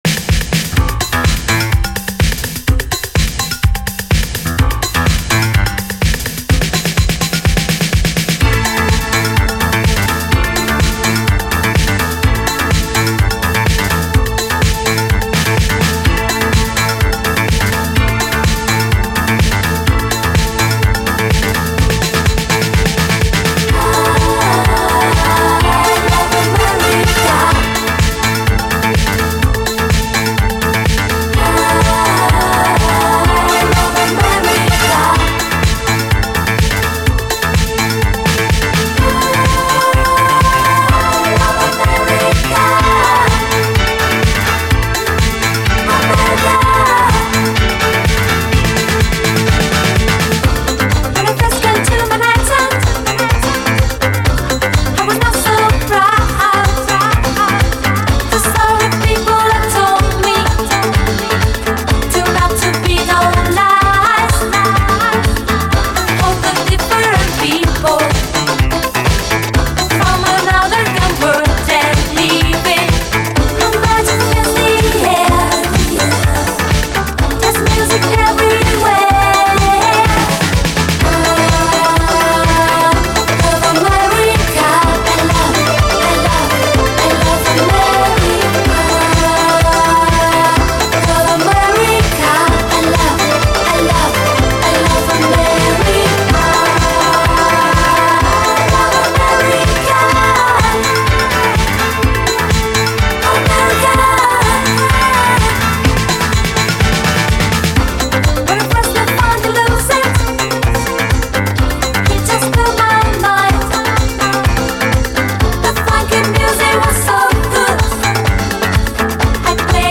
DISCO
突き抜ける疾走感の最高イタロ・ディスコ！